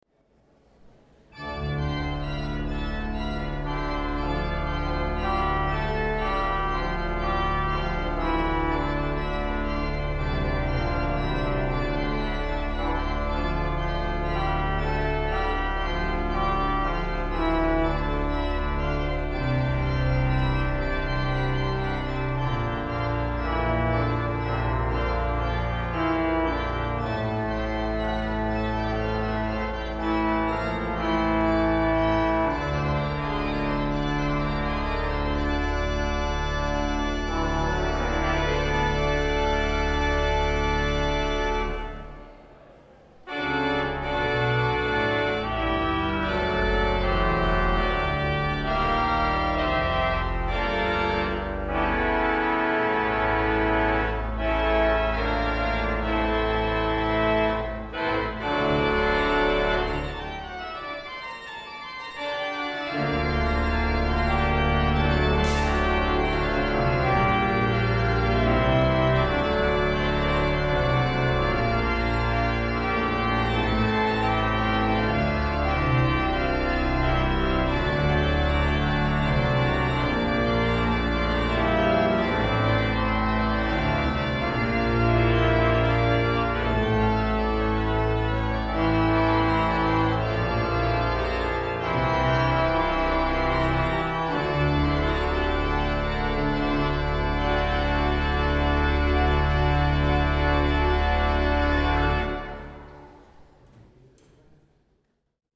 The War Memorial Organ
The organ, one of the finest on Merseyside, consists of 3 manuals, thirty five stops, 1810 pipes and (apart from the Tuba, Nazard and Piccolo) has pneumatic action throughout.
These add a beautiful sparkle to the flute chorus on the choir division.